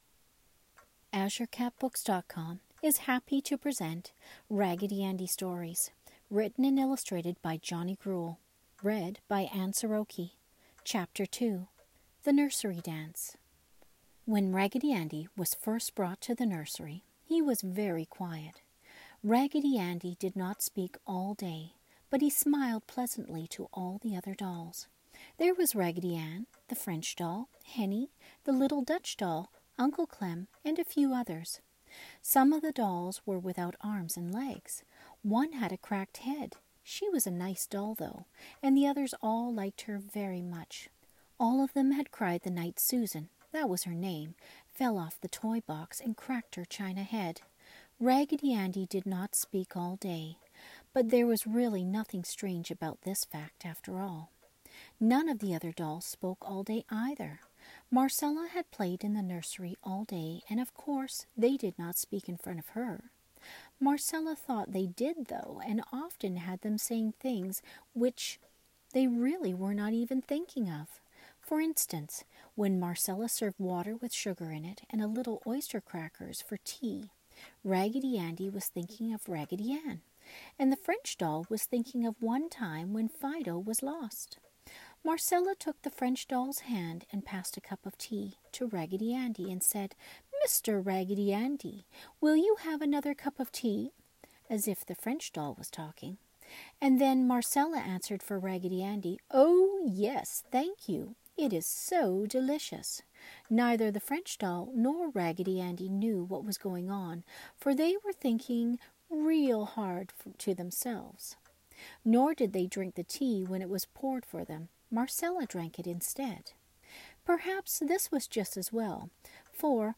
classic children's literature, children's stories, story time podcast, bed time story